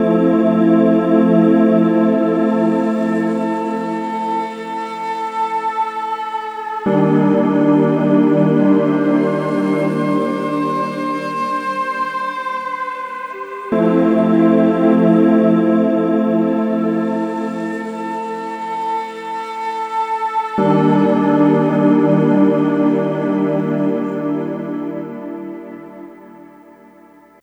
Mellow Mood 01.wav